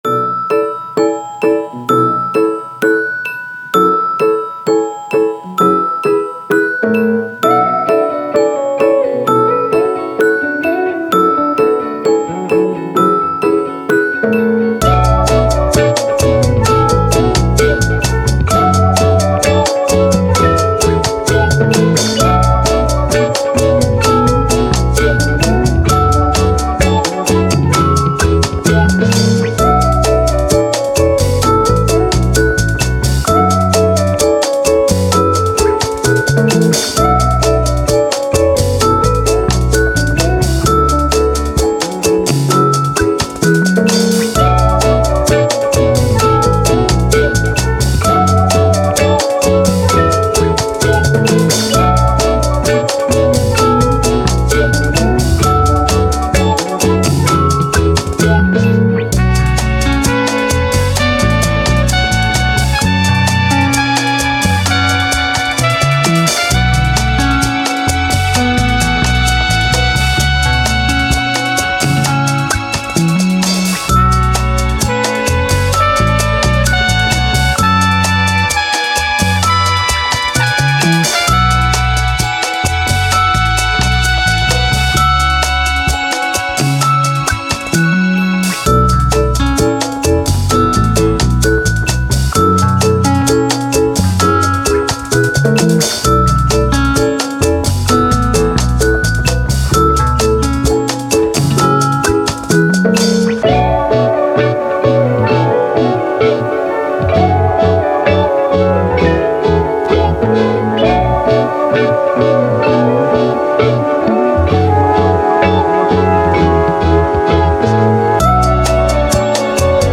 Soul, Vintage, Chilled